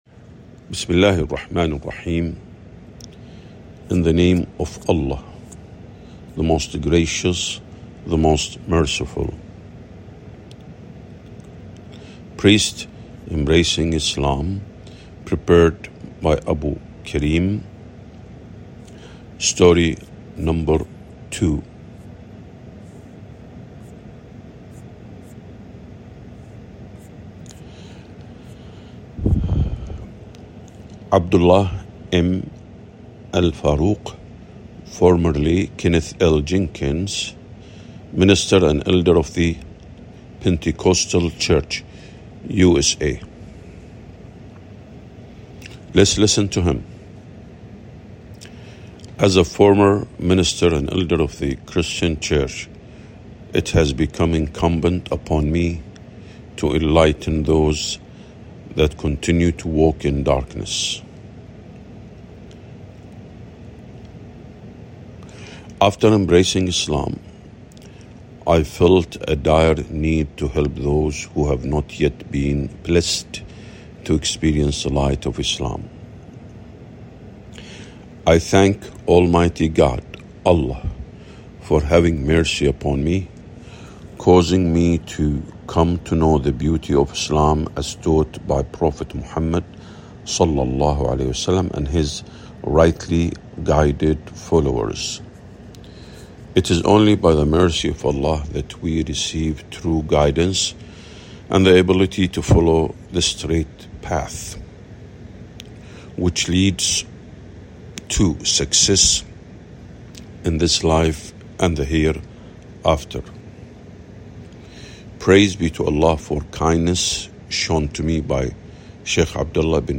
Audiobook
priests_embracing_islam_audiobook_english_2.mp3